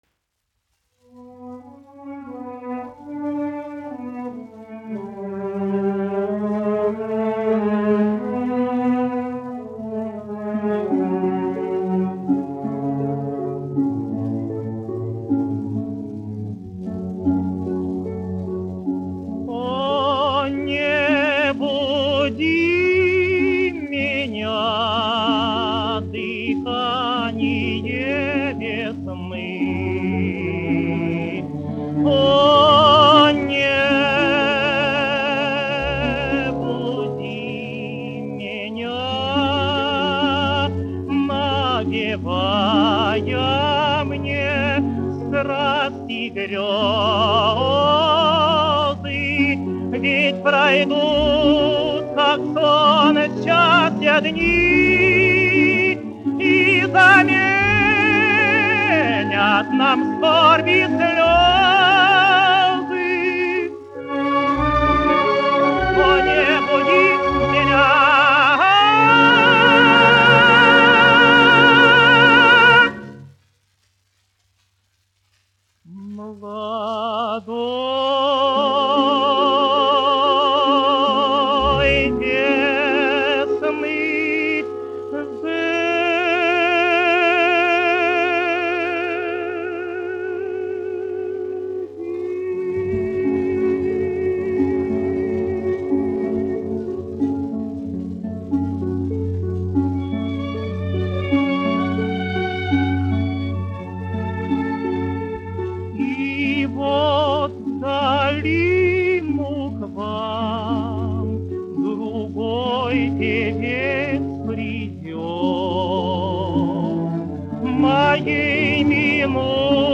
Козловский, Иван Семенович, 1900-1993, dziedātājs
Московская государственная филармония. Симфонический оркестр, izpildītājs
Александр Иванович Орлов, diriģents
1 skpl. : analogs, 78 apgr/min, mono ; 25 cm
Operas--Fragmenti
Skaņuplate